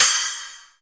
075 - SplasCym.wav